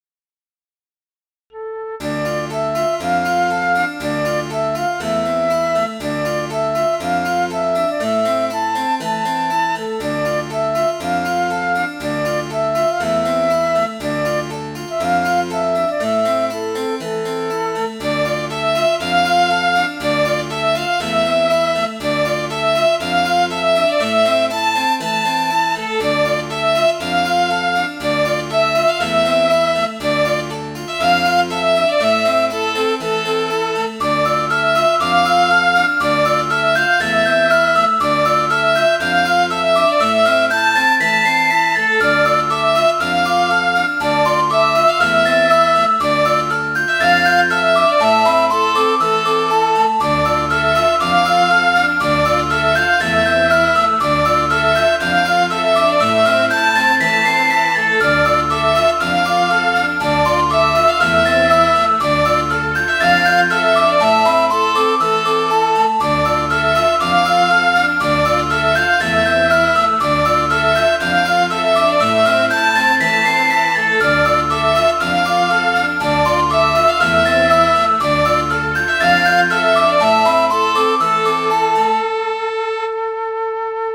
Midi File, Lyrics and Information to The Trappan'd Maiden